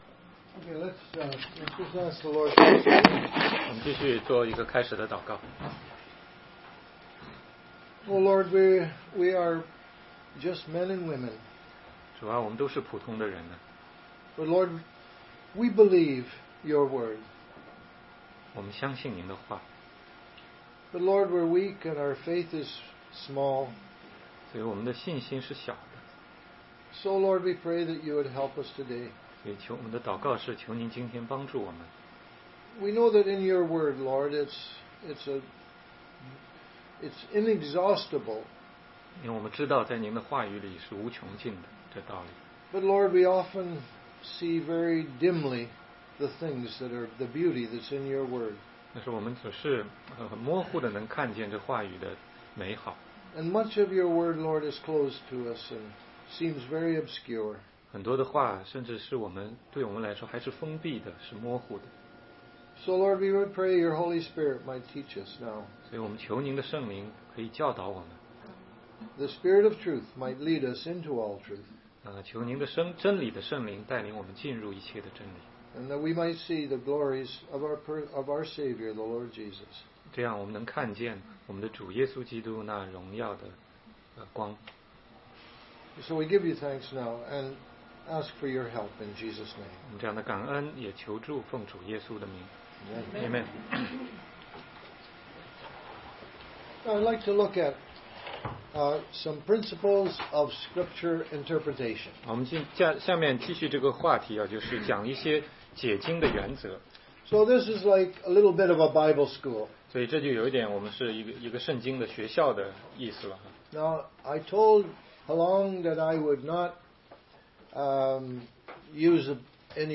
16街讲道录音 - 怎样才能读懂圣经系列之一